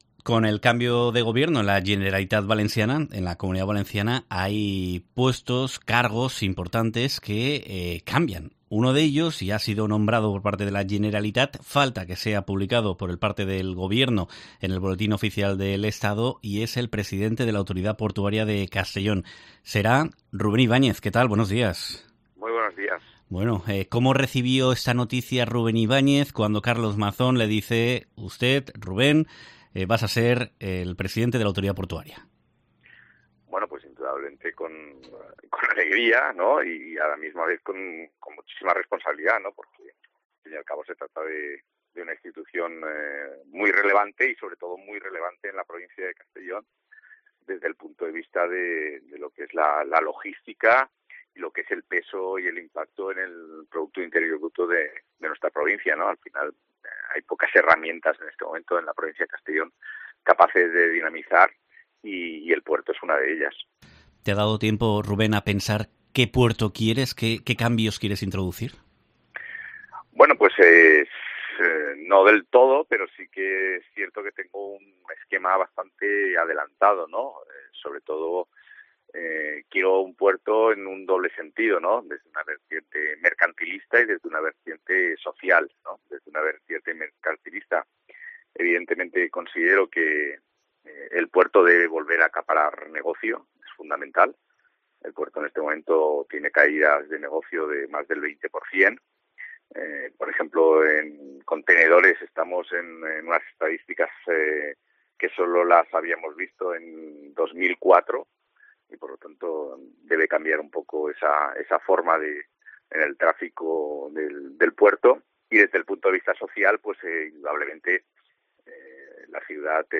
En su paso por los micrófonos de COPE Castellón, adelanta cuáles son sus planes para el futuro que le espera al puerto de Castellón.